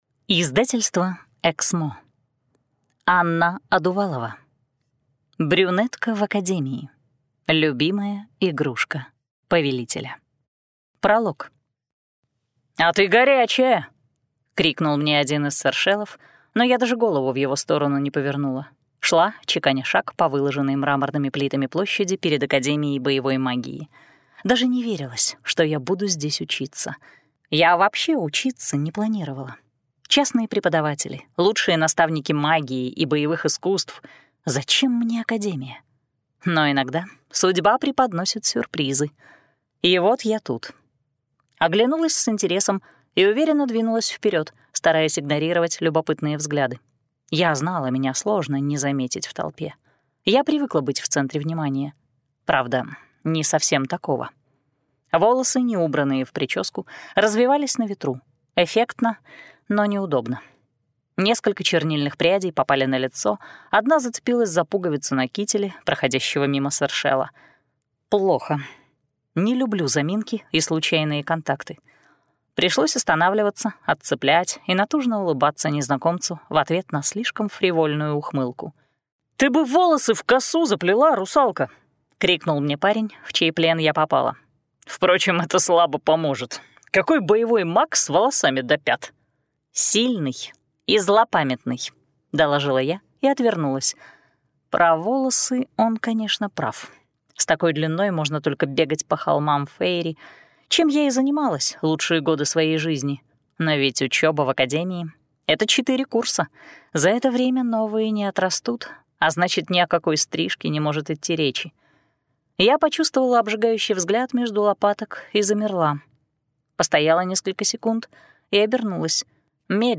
Аудиокнига Брюнетка в боевой академии. Любимая игрушка повелителя | Библиотека аудиокниг